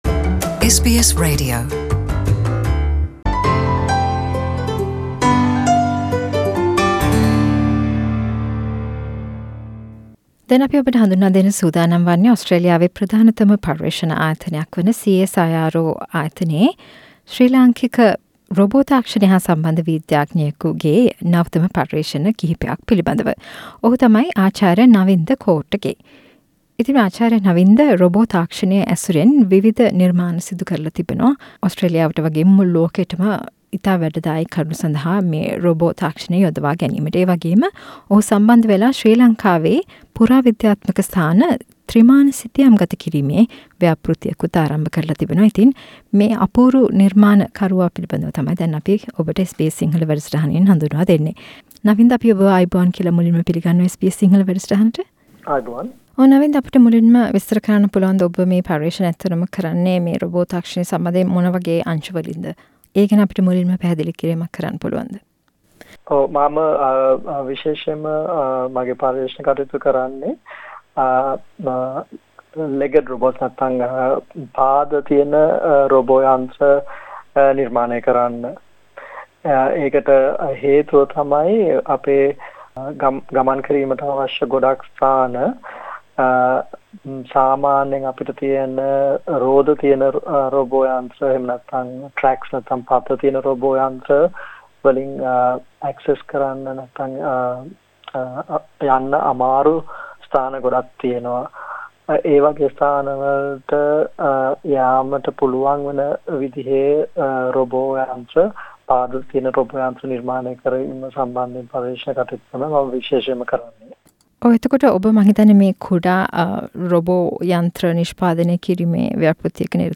මේ ඔහු සමග SBS සිංහල සිදු කල කතා බහක්